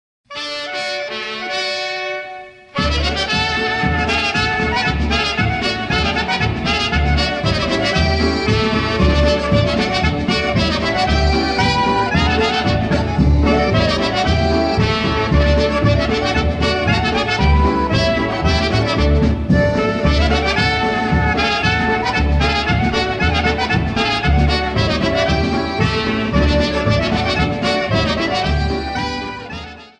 Media > Music > Polka, General